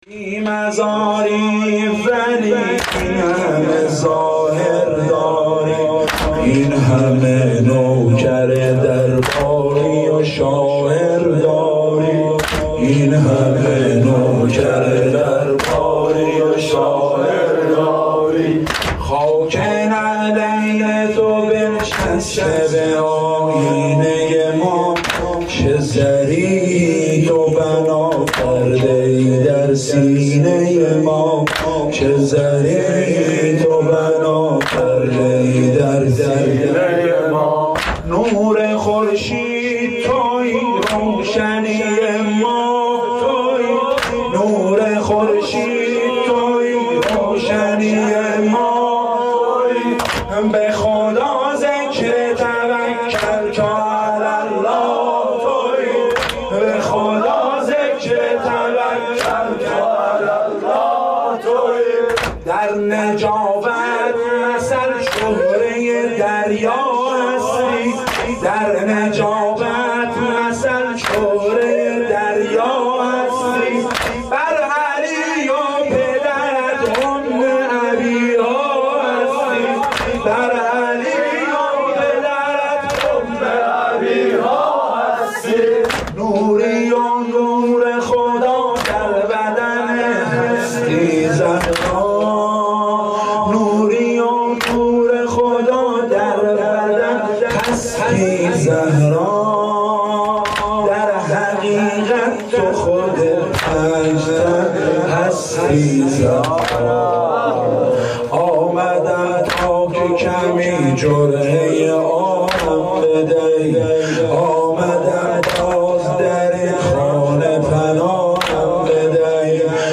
حسینیه
مداحی فاطمیه